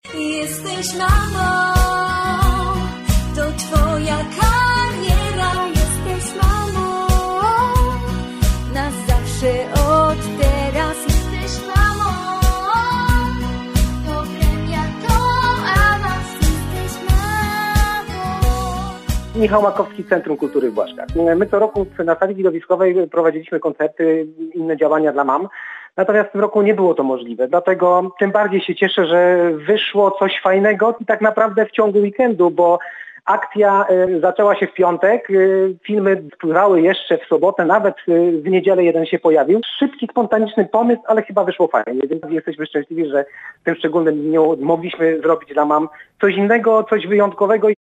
Młodzi wykonawcy nagrali w domach fragmenty piosenki, by uczcić Dzień Matki.